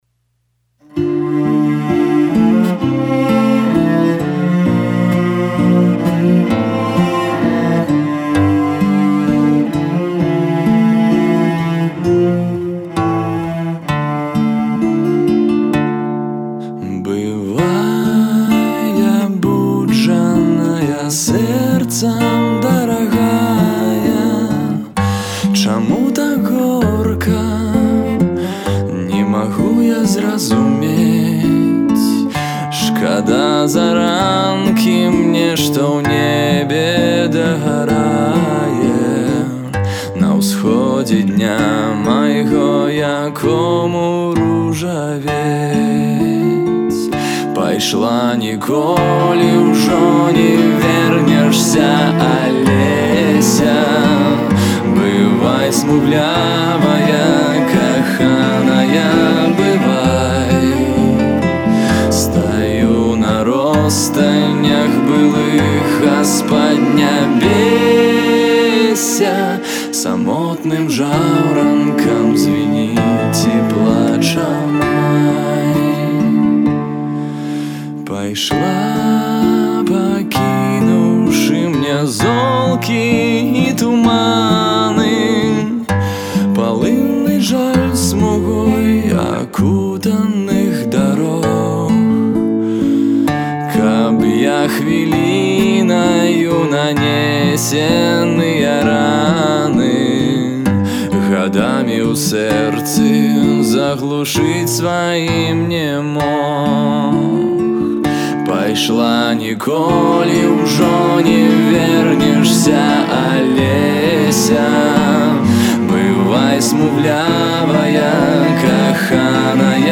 вакал, гітара
бас-гітара
запісаную з жывой віялянчэльлю.